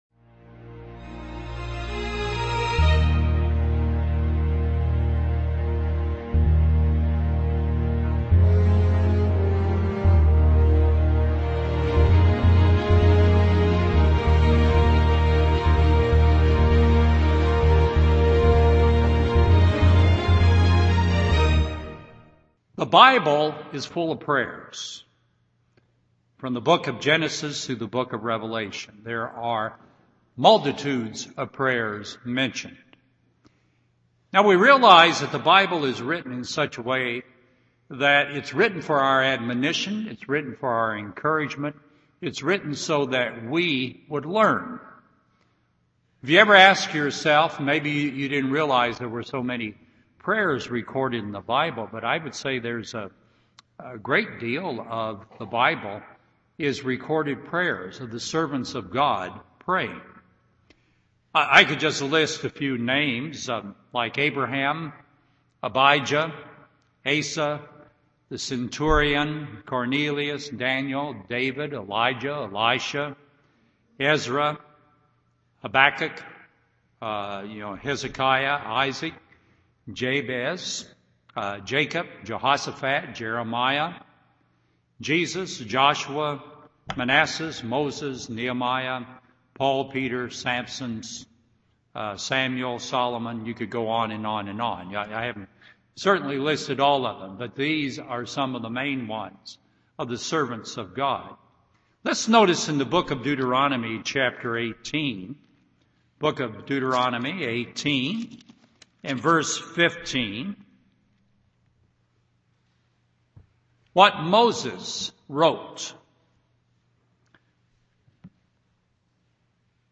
Luke 11:1-4 UCG Sermon Transcript This transcript was generated by AI and may contain errors.